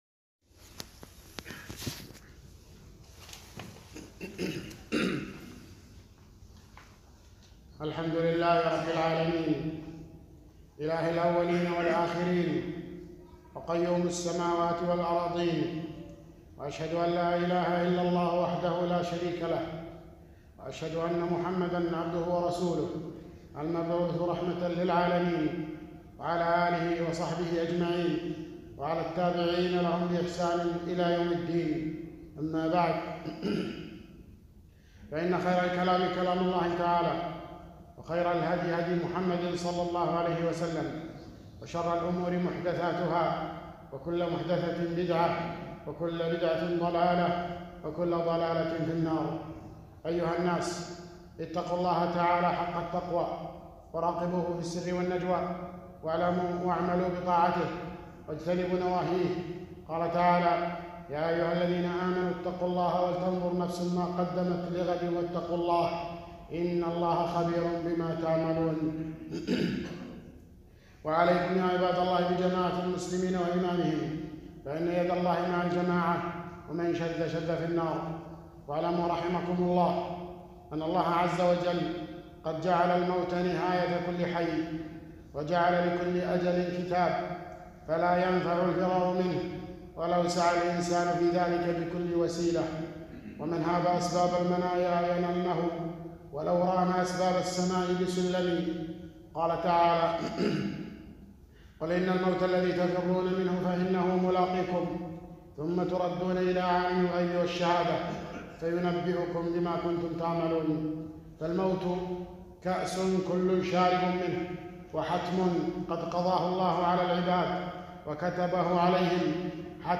خطبة - أحكام المقابر وآدابها والتحذير من بدعها